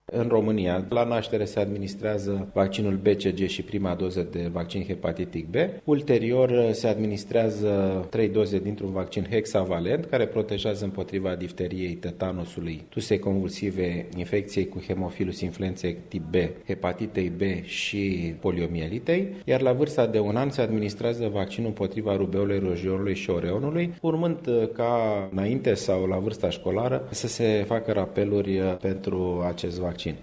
Vicepreşedintele Comitetului Naţional de Vaccinologie, doctorul Alexandru Rafila, enumeră vaccinurile cuprinse în Programul Naţional de Imunizare oferit gratuit populaţiei: